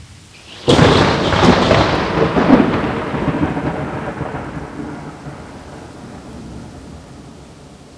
THUNDER 1 -S.WAV